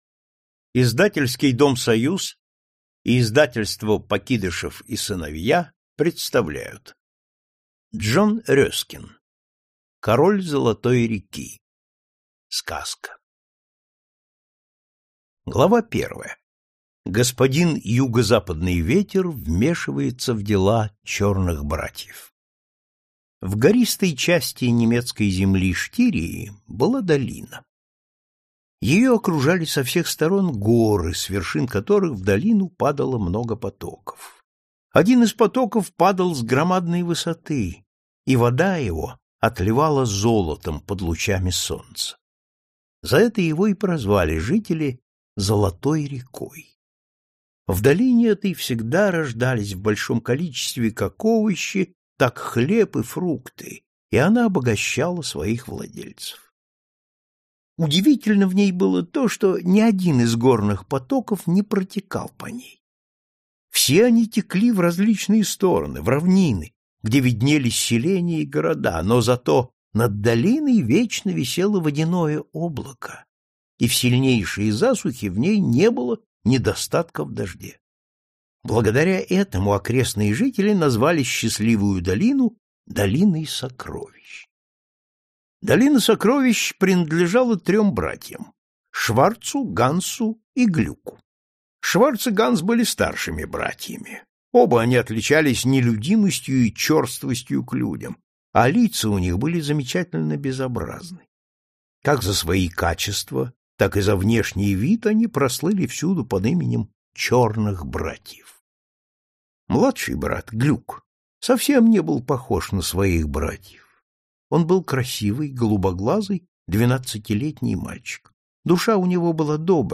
Аудиокнига Король Золотой реки | Библиотека аудиокниг